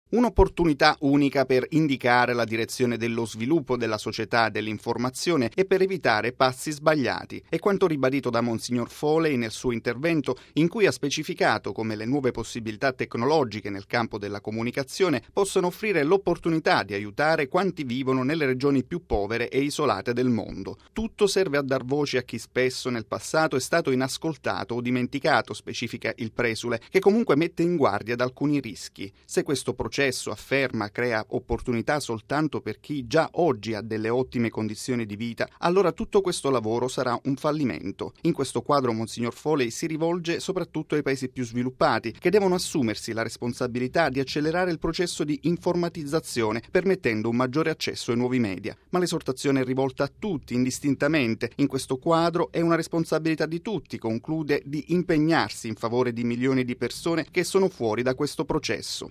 (18 novembre 2005 - RV) Continua a Tunisi il summit internazionale dell’Onu sull’informazione. Stamani l’intervento dell’arcivescovo, John Foley, presidente del Pontificio Consiglio delle Comunicazioni Sociali.